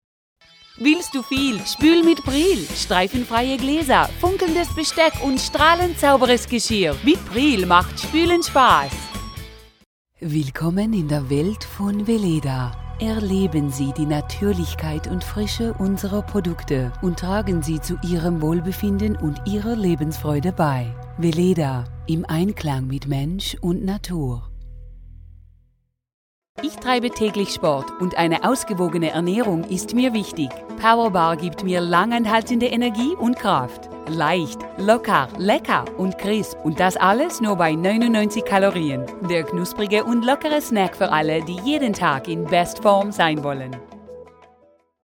freundlich, ehrlich, authentisch, sinnlich, warm, variabel, seriös, sympatisch, wandlungsfähig, emotional
Sprechprobe: Werbung (Muttersprache):
conversational, friendly, real, soothing, educational, informative, warm